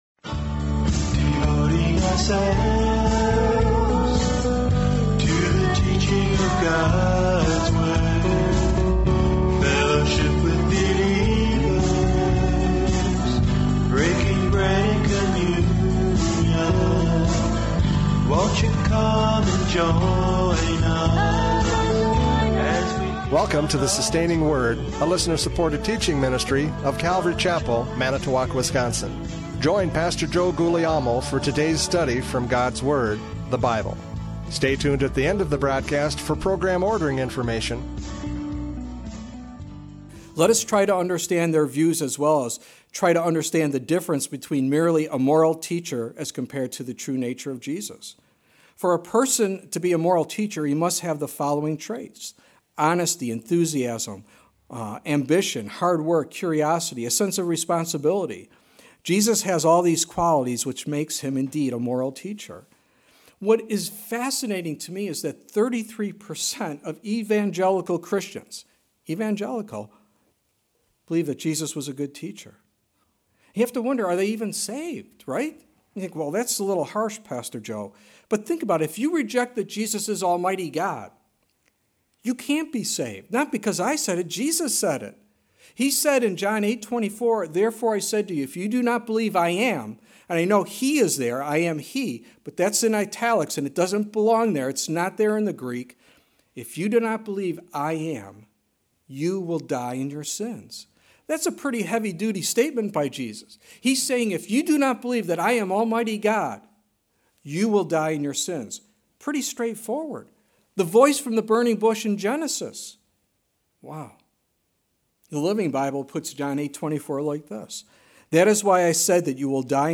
Radio Programs « John 1:29-34 John the Baptist’s Testimony!